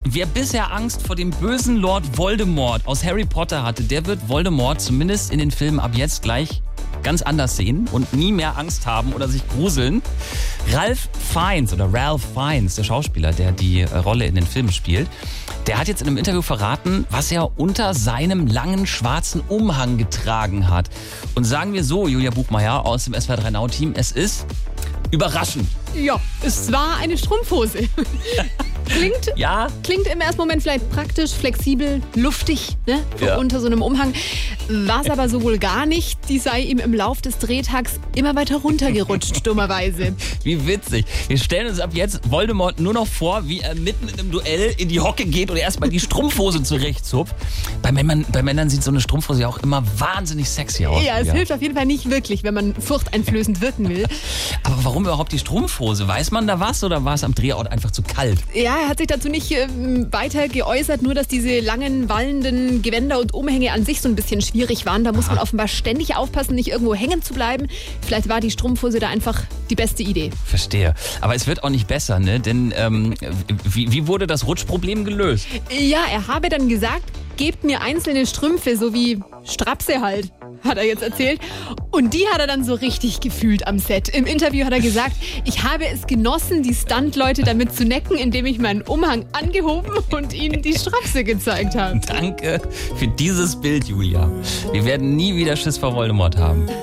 Nachrichten Keine Angst mehr vor Voldemort: Er trug Strapse!